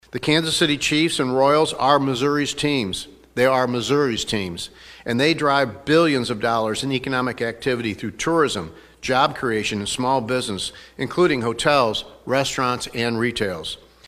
There's a bidding war going on for the next stadium location of the Chiefs and the Royals: Kansas wants to pull the teams to its side of the stateline, while many Missourians have made clear they want those team to STAY. That includes Governor Kehoe, speaking at a press conference last week, which follows: